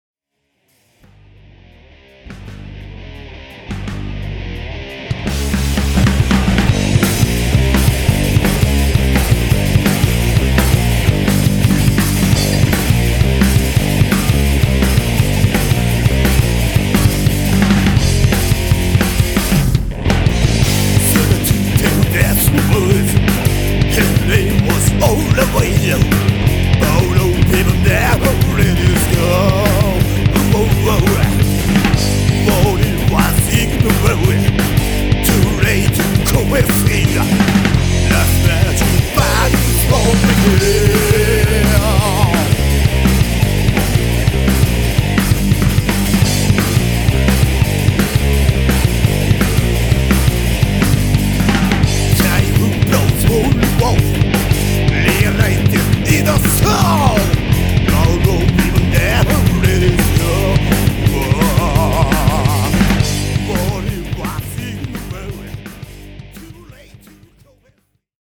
この曲はイントロから叩き出されるパワフルなサウンドが心地よい。